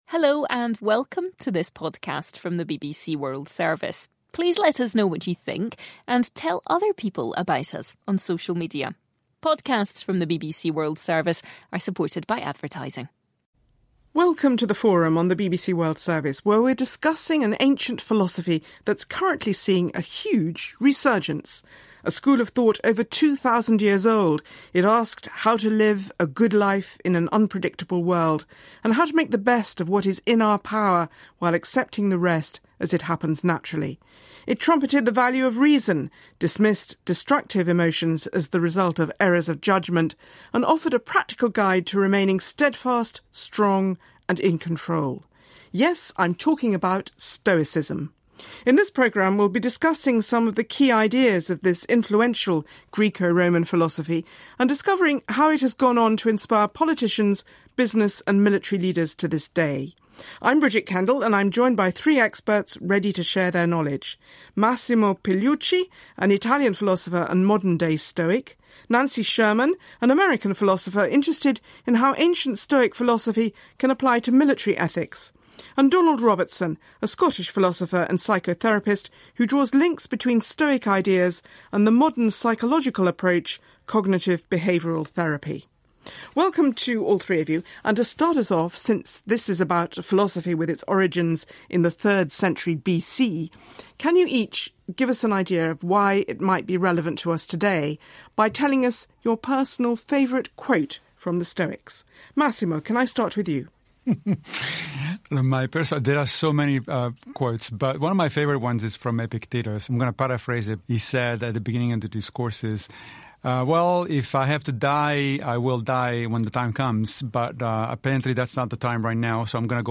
o estoicismo é debatido pelos convidados